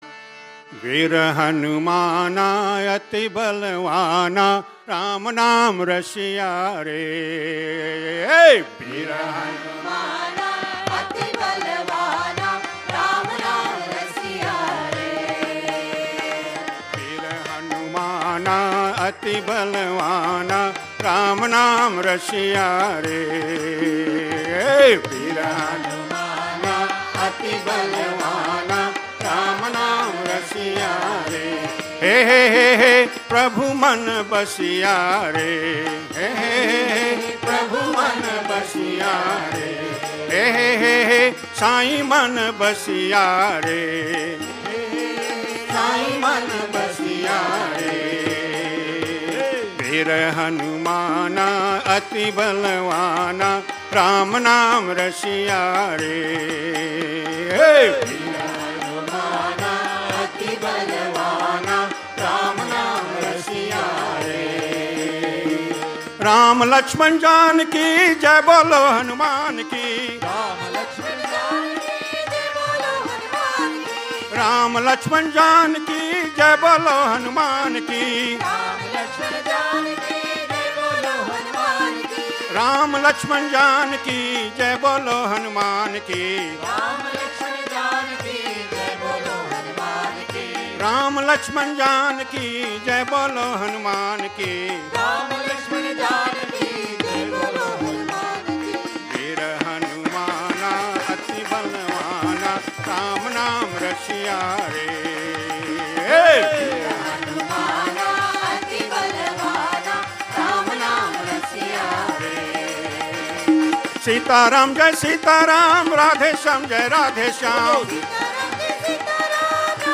Index of /media/R05_Retreat_September_2017/Bhajans/Saturday_PM_Bhajans